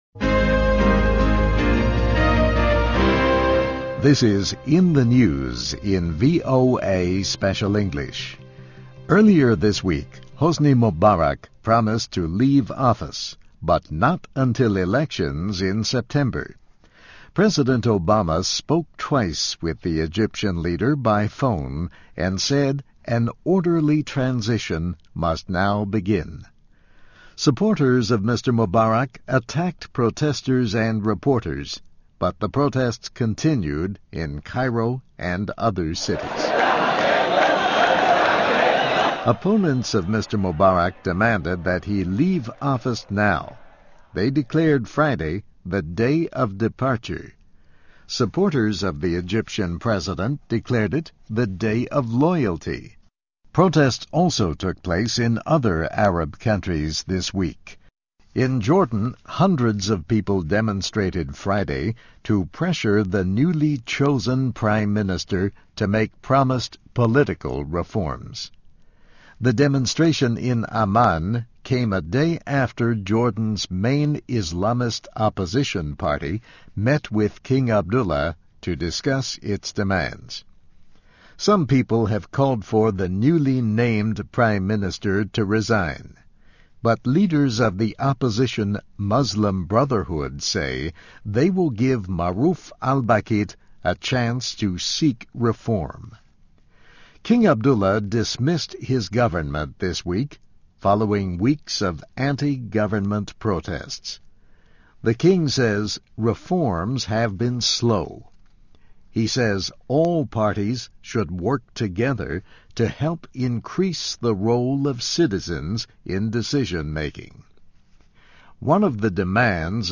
美国之音VOA Special English > In the News > What Kinds of Changes Will Arab Unrest Bring?